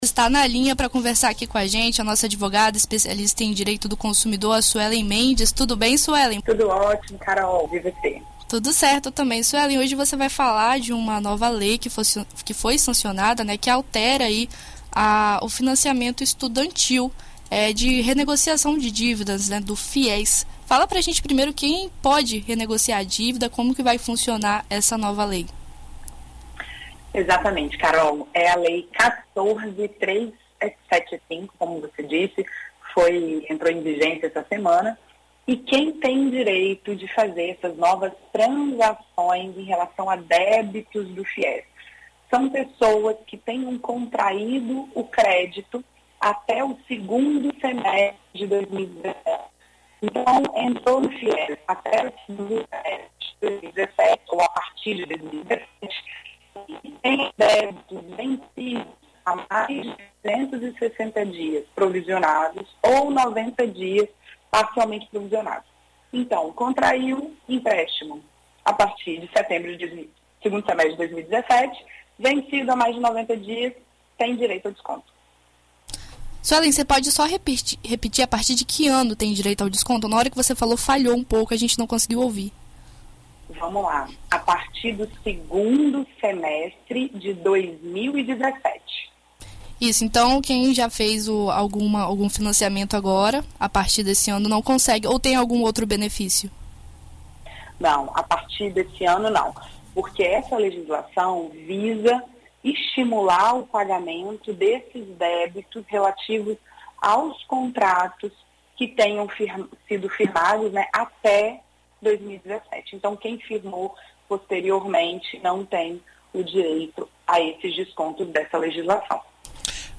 Na coluna Consumo e suas garantias desta sexta-feira (24), na BandNews FM Espírito Santo